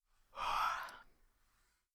yawn1.wav